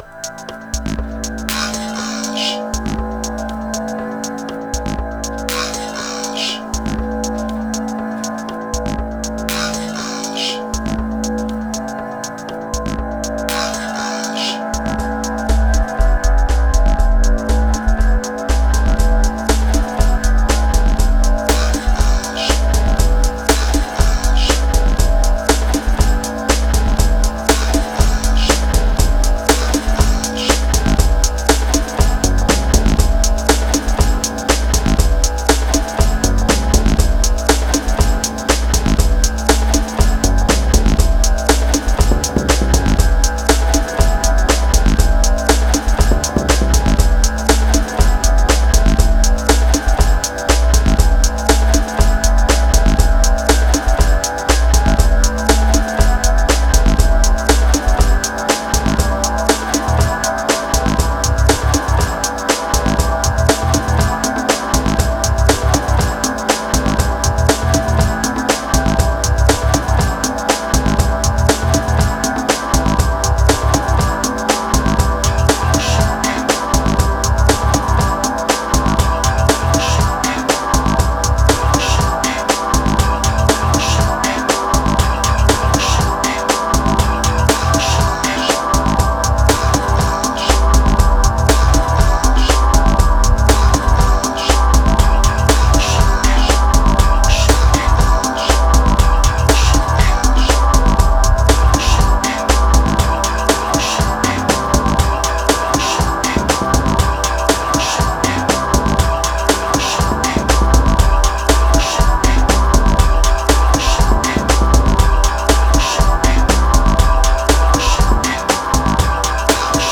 801📈 - 71%🤔 - 120BPM🔊 - 2020-11-03📅 - 580🌟
Triphop Electric Beat Energy Dark Quotes